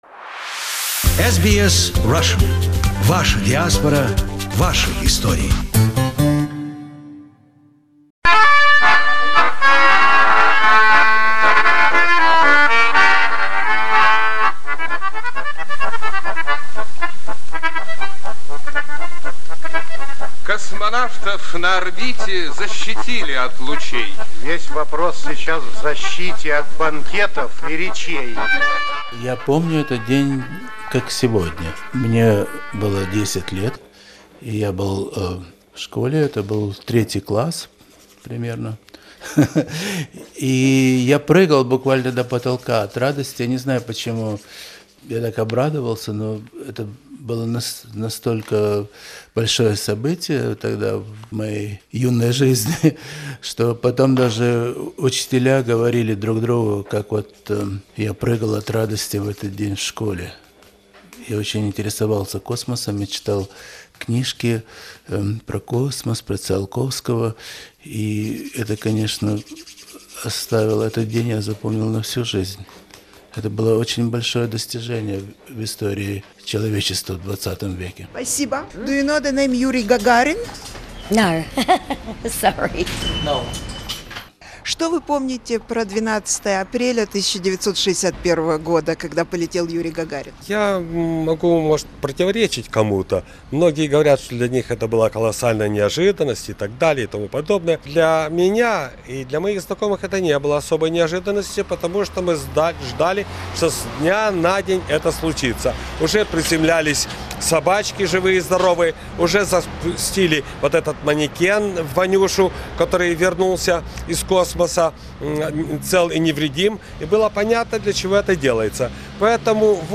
This program was dedicated to the 50th anniversary of the first human to journey into outer space, when Vostok spacecraft completed one orbit of the Earth on 12 April 1961. In April 2011 we asked people on Melbourne streets who Yuri Gagarin was and what they remembered from this remarkable day of the last Century.